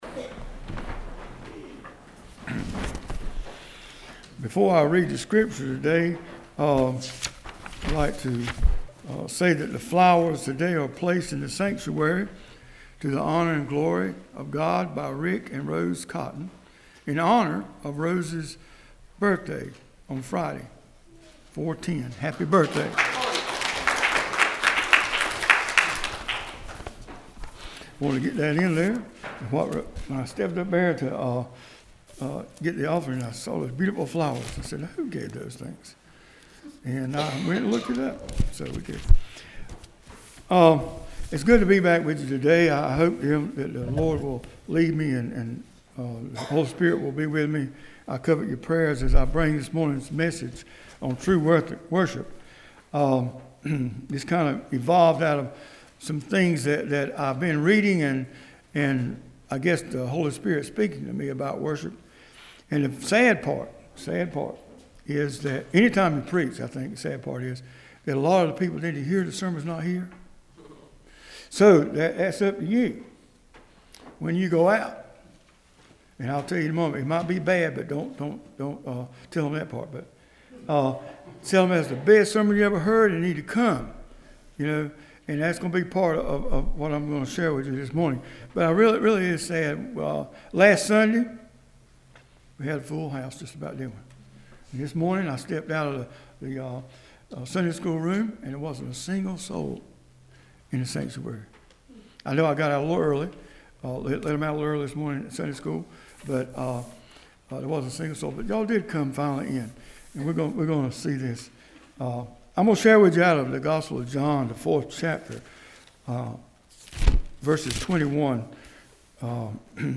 Worship Service 4-12-15: True Worship
This weeks scripture and sermon: Scripture : John 4:21-24 Sermon